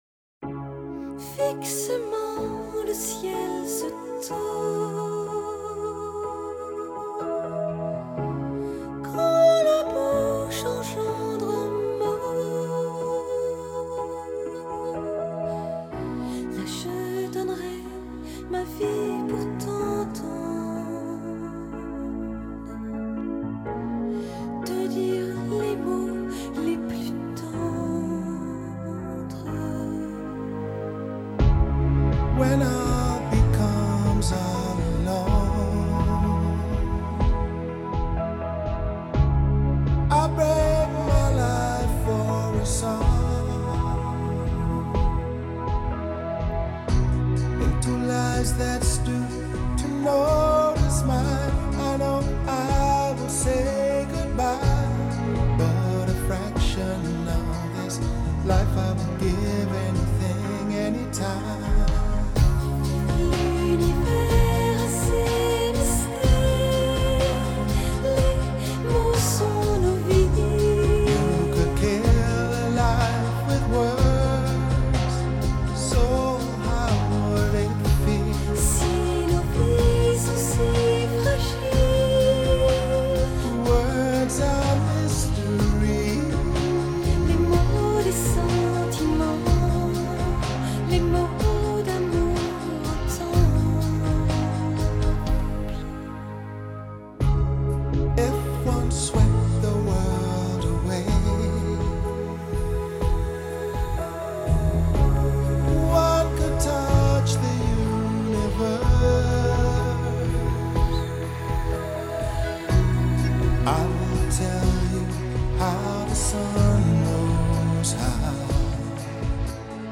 Жанр: Pop; Битрэйт